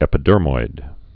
(ĕpĭ-dûrmoid)